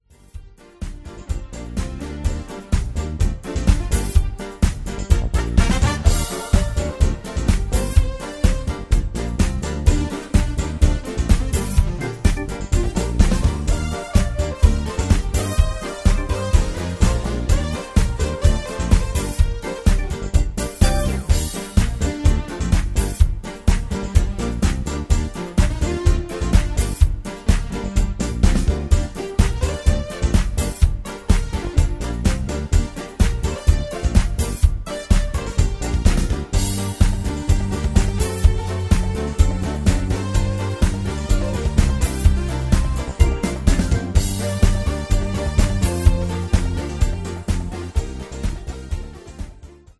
Category: Patter Tag: Called Plus